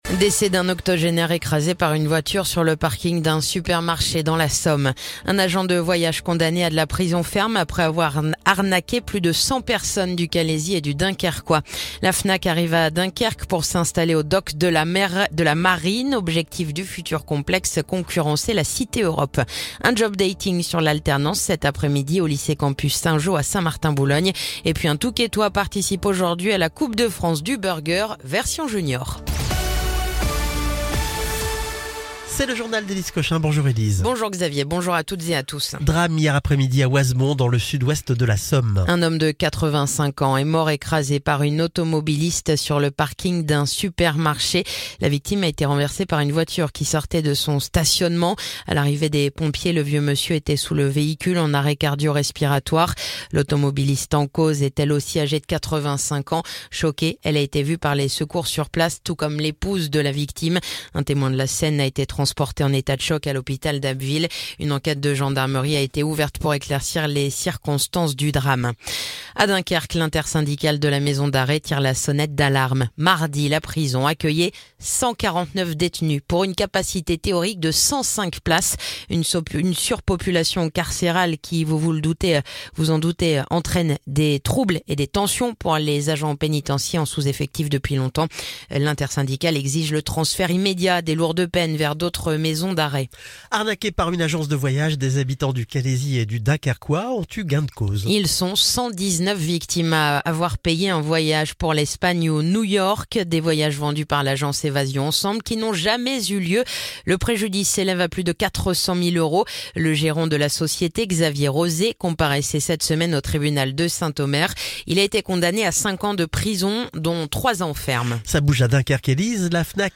Le journal du jeudi 3 avril